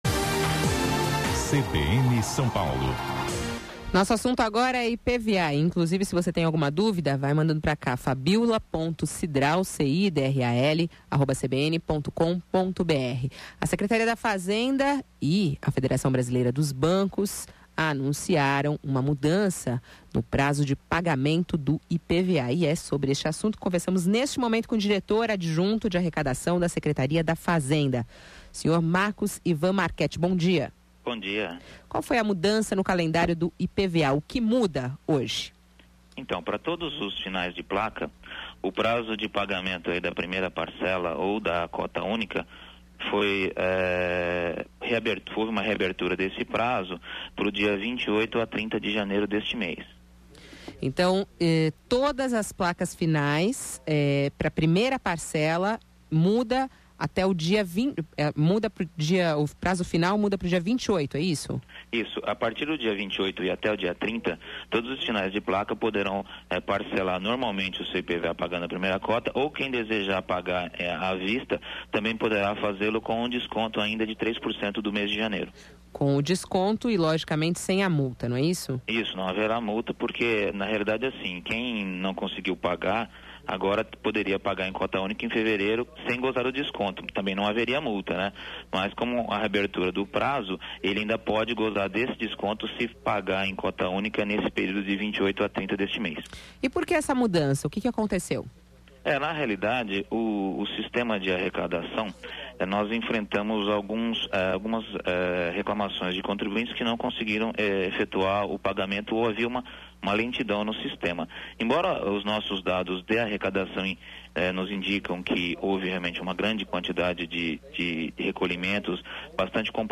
Ouça a entrevista sobre o IPVA